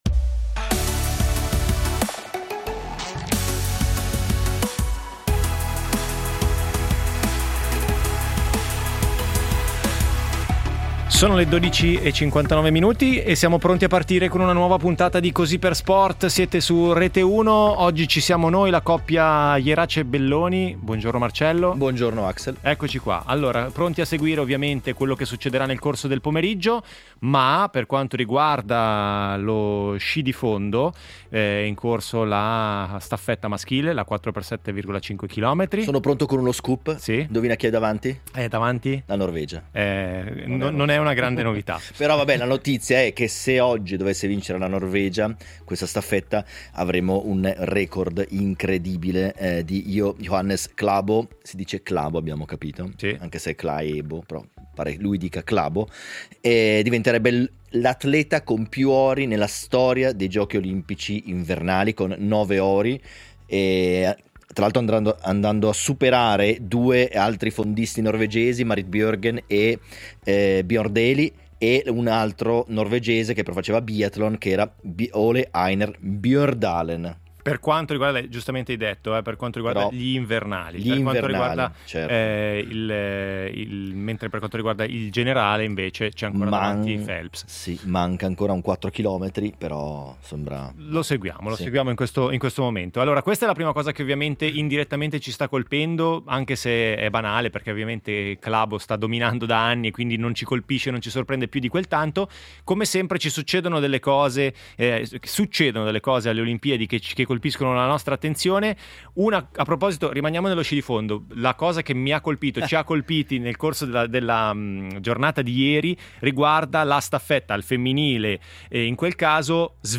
Tornano le Olimpiadi e torna Così per sport per raccontarvi minuto per minuto i Giochi di Milano-Cortina. I risultati, il medagliere, le voci degli inviati e le storie degli ospiti che hanno vissuto sulla propria pelle la rassegna a cinque cerchi, ma anche i dietro le quinte e le curiosità delle varie discipline per immergerci tutti insieme – sportivi e meno – nello spirito olimpico.